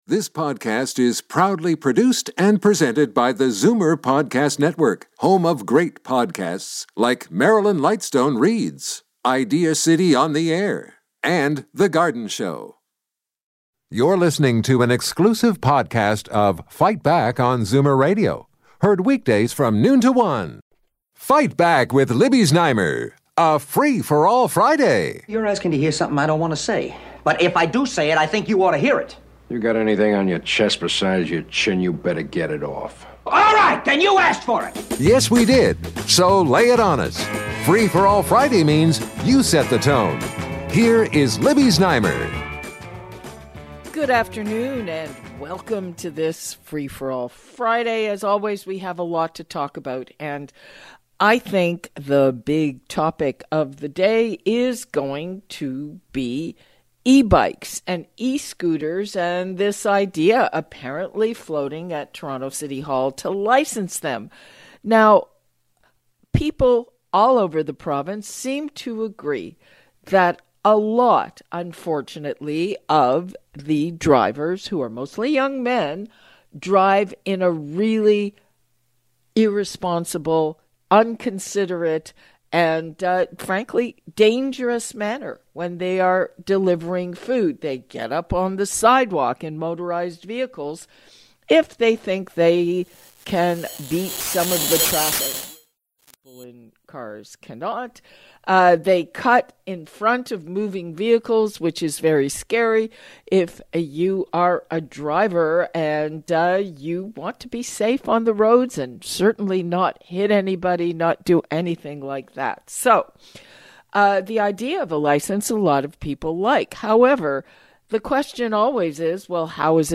Today on Free For All Friday: listeners reacted to the news of Toronto Mayor Olivia Chow getting rid of the Toronto Parking Authority board this week and other municipal issues like speed cameras. … continue reading 502 episodes # Politics # News Talk # News # Libby Znaimer # Zoomer Podcast Network # Medical Record